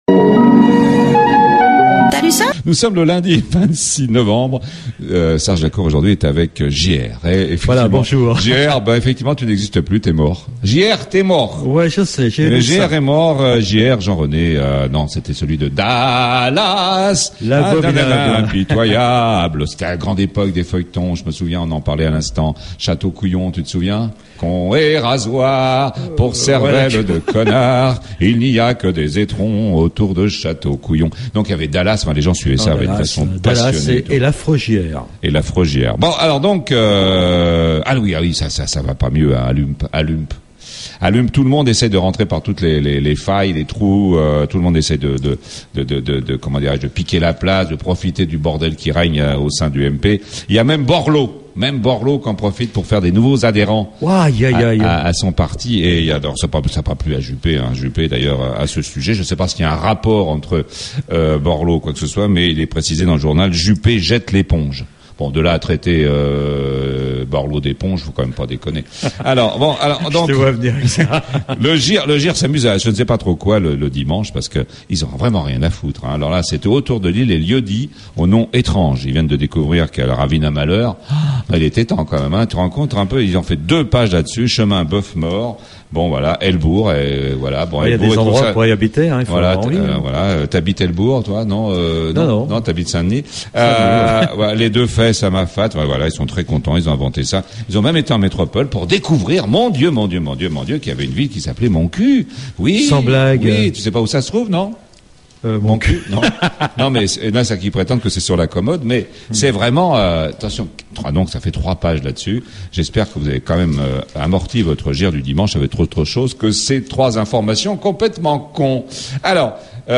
La Revue de Presse la plus drôle, la moins sérieuse, la plus décalée, la moins politique, et la plus écoutée sur PLUS FM 100.6 dans le Nord, et 90.4 dans l'Ouest...